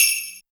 07_Hats_16_SP.wav